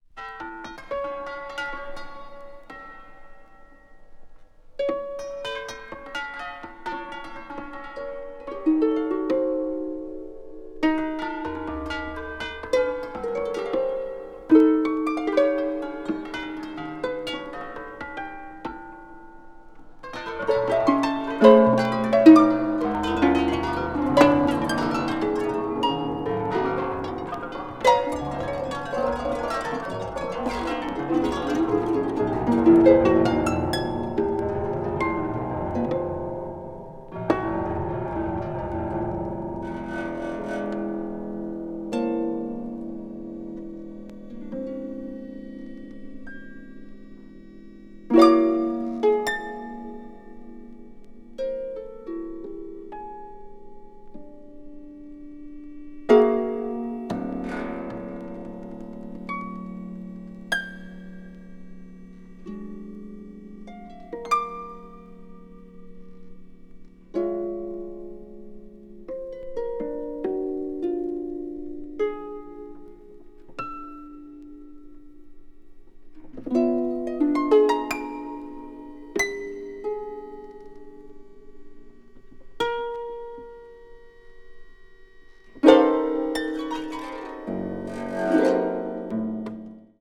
media : NM-/NM-(a few slightly noises.)